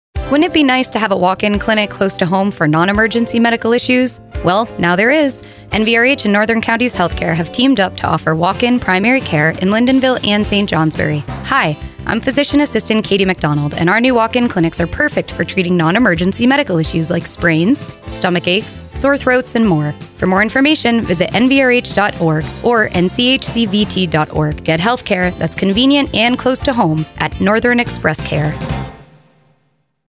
NEC-Radio-Spot-22521.mp3